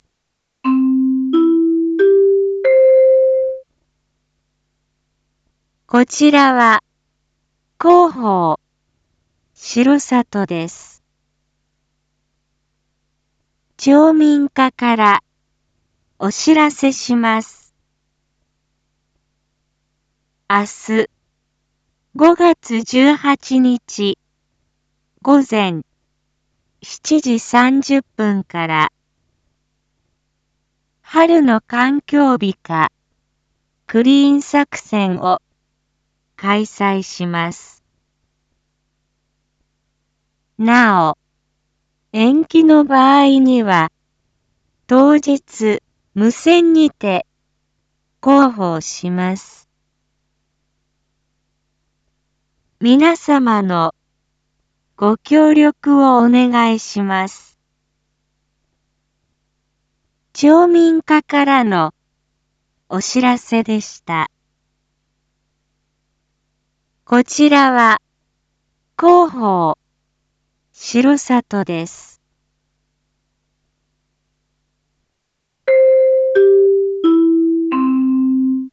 Back Home 一般放送情報 音声放送 再生 一般放送情報 登録日時：2025-05-17 19:01:25 タイトル：春の環境美化クリーン作戦 インフォメーション：こちらは、広報しろさとです。